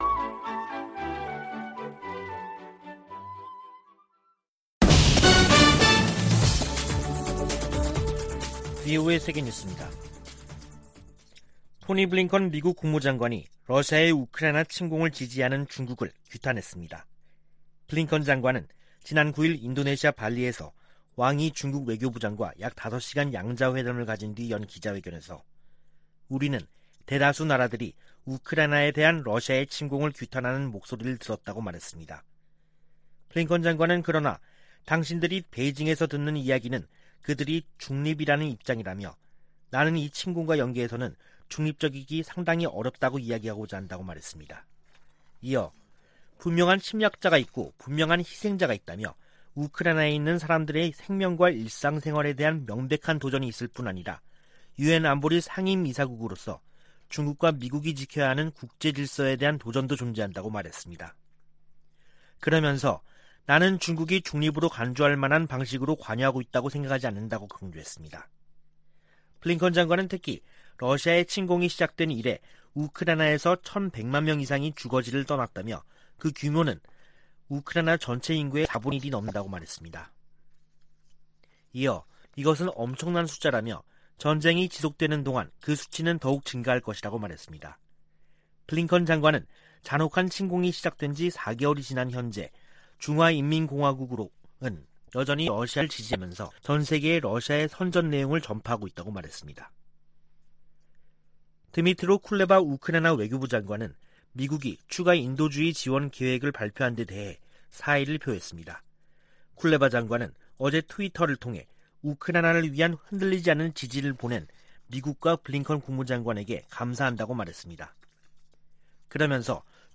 VOA 한국어 간판 뉴스 프로그램 '뉴스 투데이', 2022년 7월 11일 2부 방송입니다. 북한이 한 달 만에 또 다시 서해로 방사포 2발을 발사했습니다. 미-한-일은 주요 20개국(G20) 외교장관 회의를 계기로 3자 회담을 갖고 안보협력 확대 방안을 협의했습니다. 미국과 한국이 다음달 22일부터 9월 1일까지 미-한 연합지휘소훈련(CCPT)을 진행하기로 했습니다.